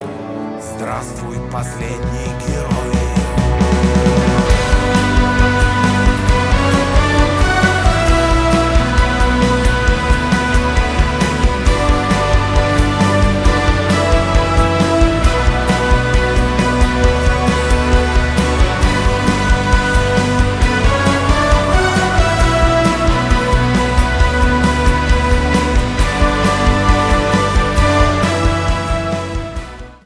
русский рок , саундтрек